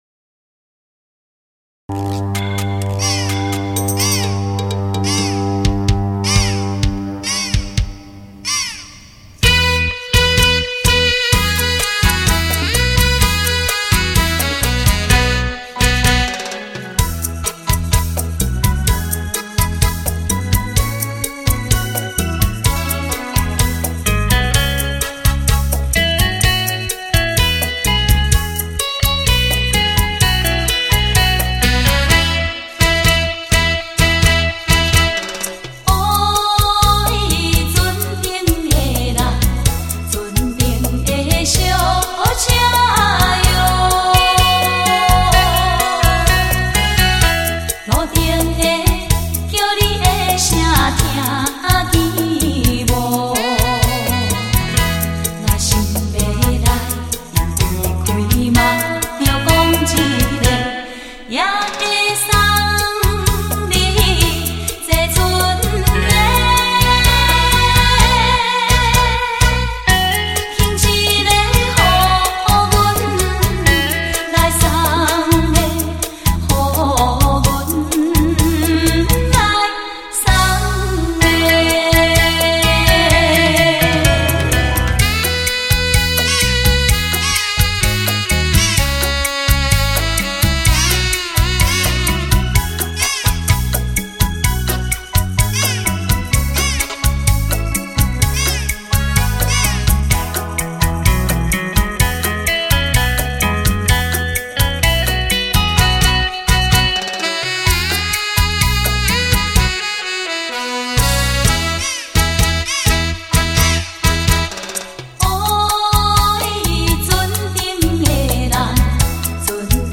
恰恰